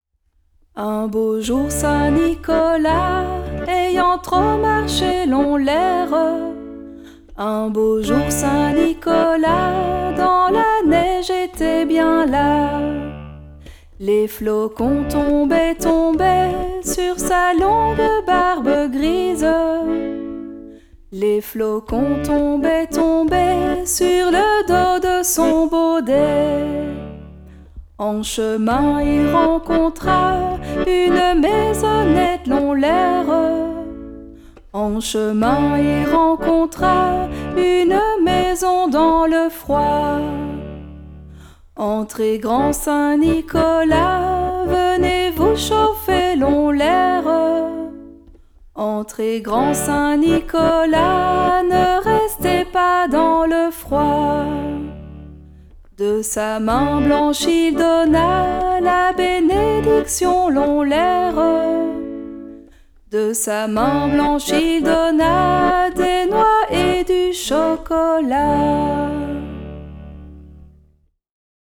chant
accordéon